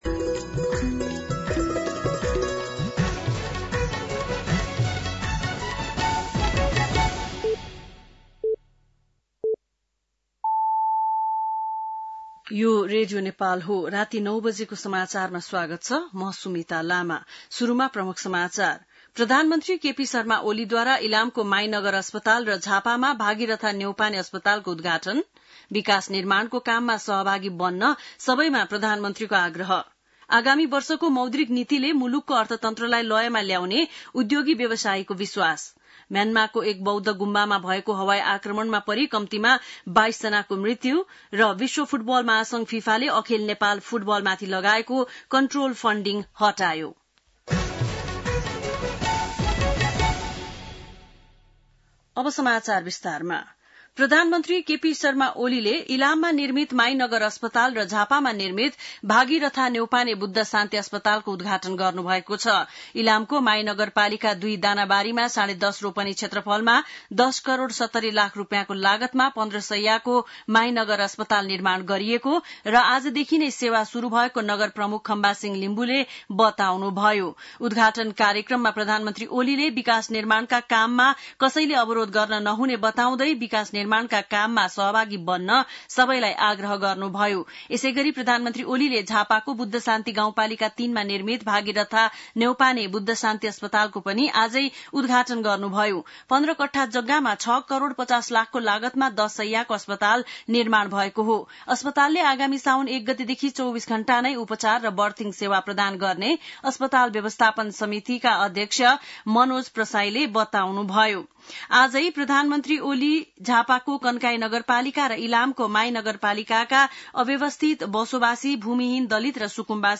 बेलुकी ९ बजेको नेपाली समाचार : २८ असार , २०८२
9-PM-Nepali-NEWS-03-28.mp3